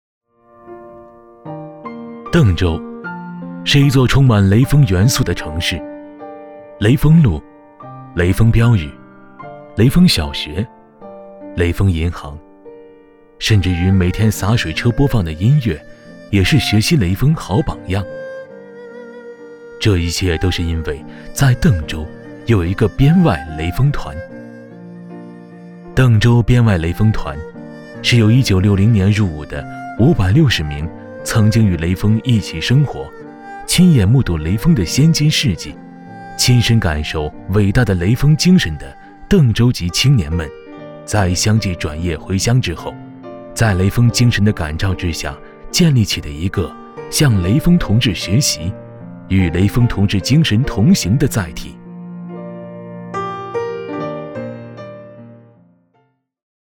旁白-男19-邓州.mp3